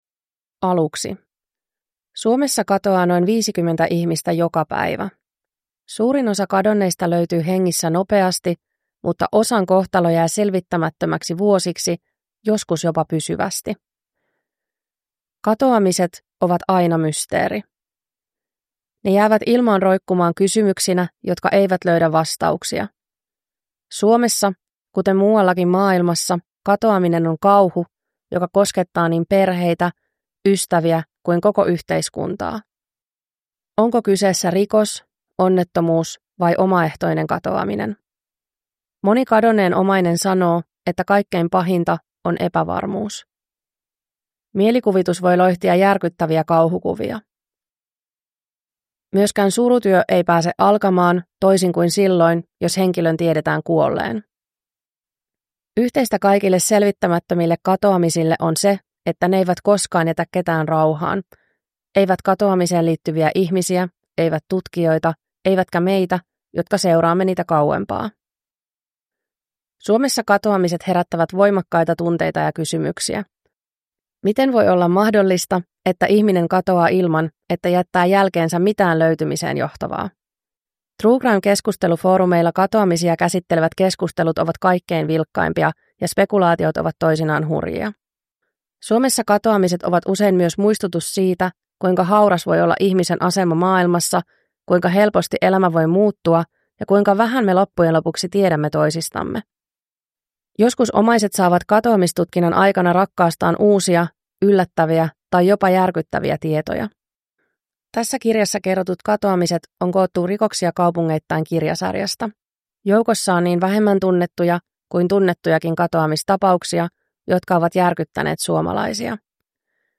Kadonneet (ljudbok) av Linda Rantanen